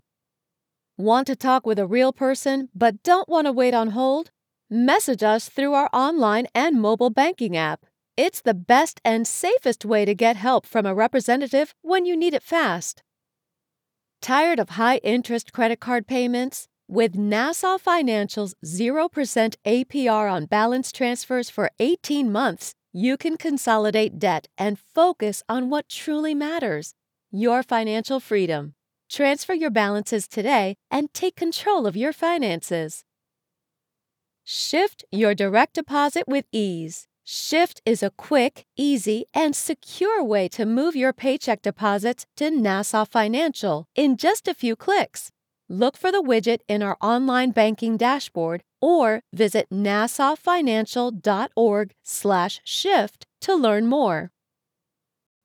On Hold, Professional Voicemail, Phone Greetings & Interactive Voice Overs
Adult (30-50)